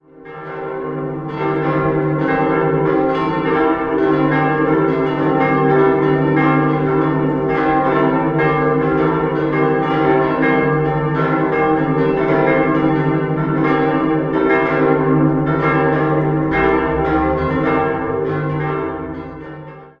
Nach einem Turmbrand wurde dieser im Jahr 1768 wieder aufgebaut. 5-stimmiges Geläute: c'-e'-g'-a'-h' Die zwei kleinen und die große Glocke wurden 1786 von Lorenz Kraus in München gegossen, die beiden anderen stammen von Karl Czudnochowsky aus dem Jahr 1949.